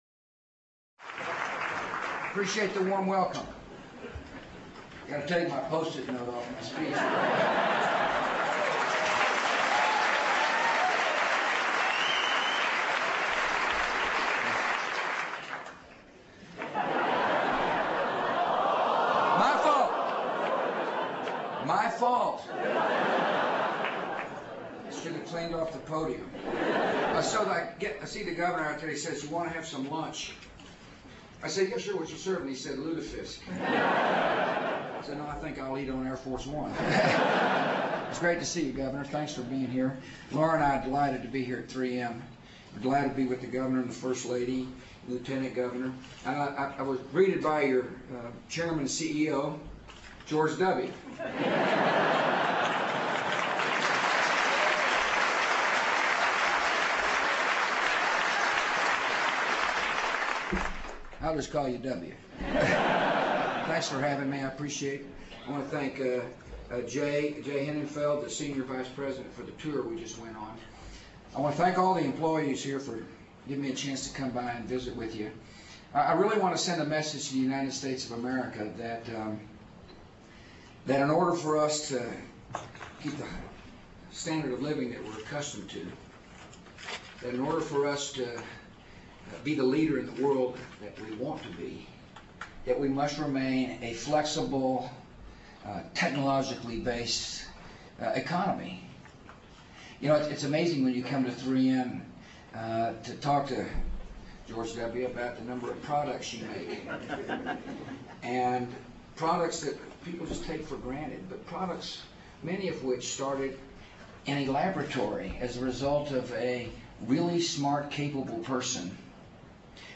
President George W. Bush speaks on the American Competitiveness Agenda at the 3M Corporate Headquarters in Minnesota. Bush discusses the uncertainty in America today due to the economy and emerging competitors in India and China. Bush says the way to make the U.S. a world leader again is to energize the economy with tax relief, keeping markets open, affordable medicine, and more research and development jobs.